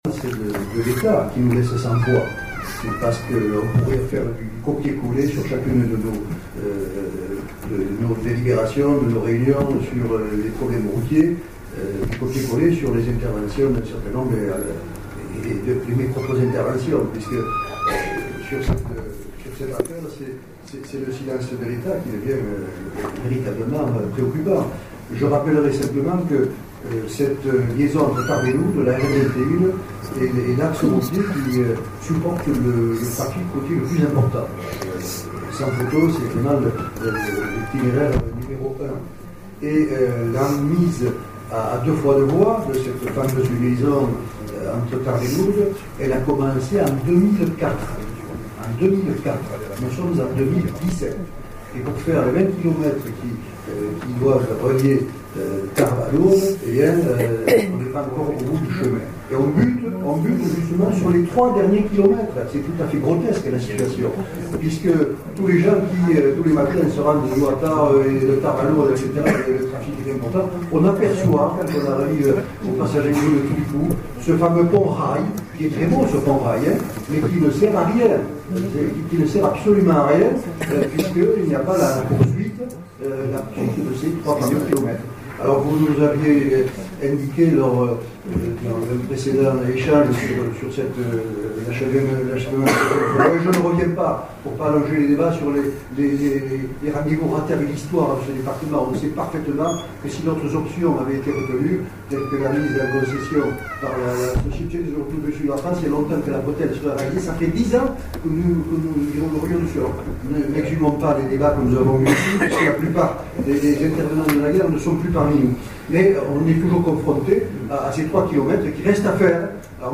Maintenant on butte sur les 3 derniers kms. Michel Pélieu et Jean Glavany interviennent.